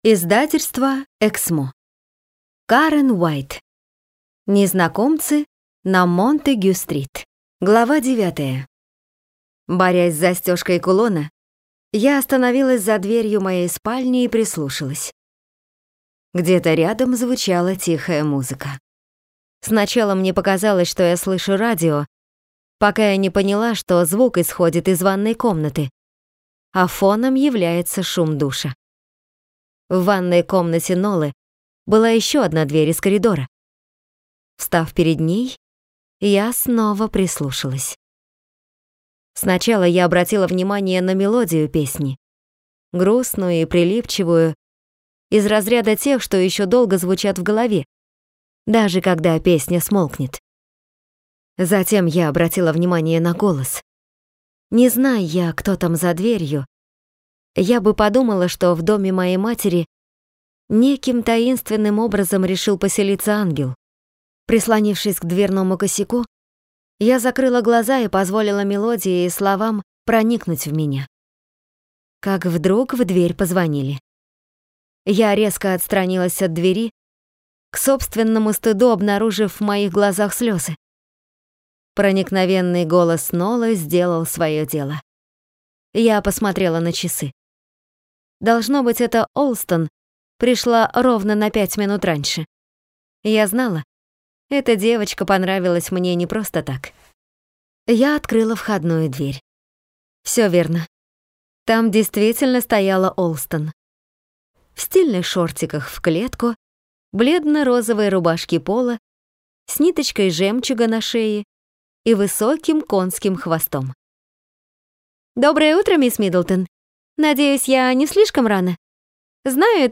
Аудиокнига Незнакомцы на Монтегю-стрит | Библиотека аудиокниг